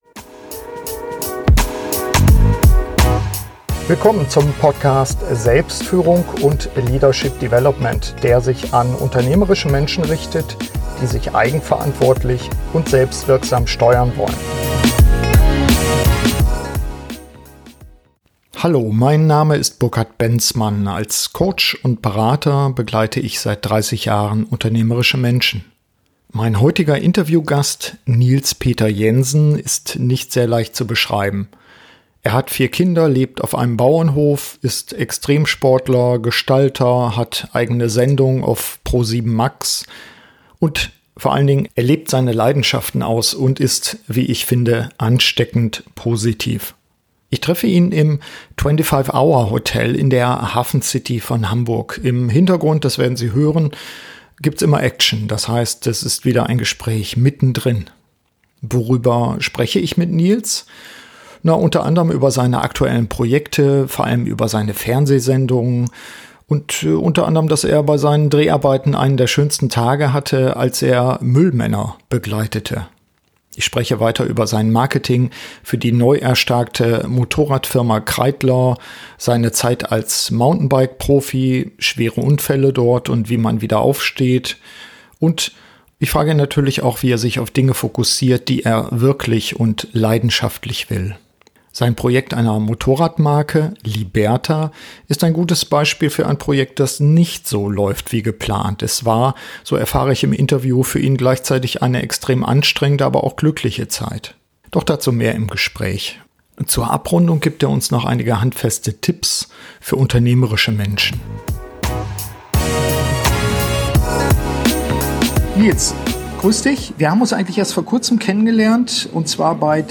Im Interview lasse ich mich von seiner extremen Selbstführung und seiner Art, leidenschaftlich zu leben, begeistern.